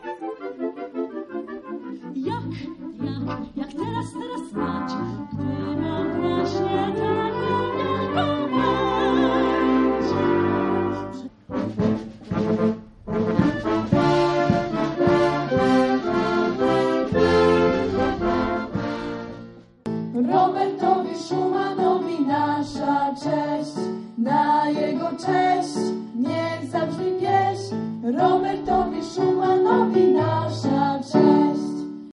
Oprawę muzyczną stanowiły występy Orkiestry Wojskowej z Torunia
1_orkiestra.mp3